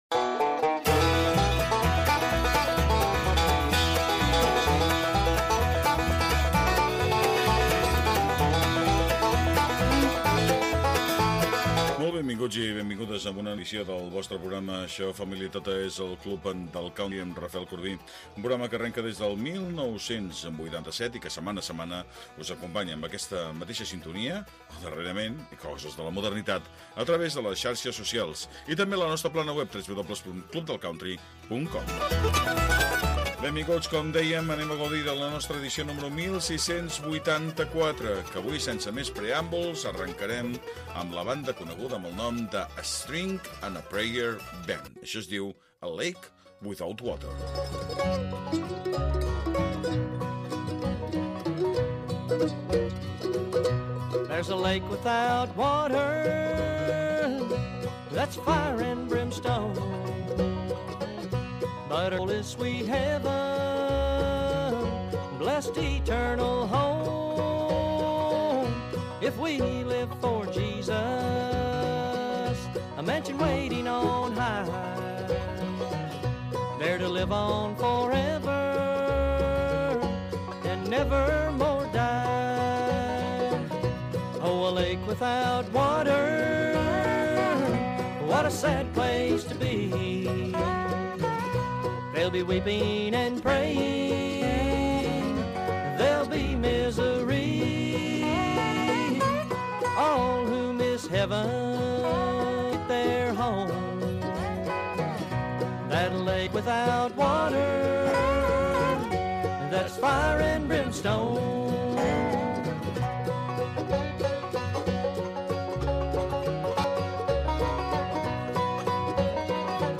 El club del country. Programa de música country. Durant 60 minuts escoltaràs els èxits del moment i els grans clàssics de la música country.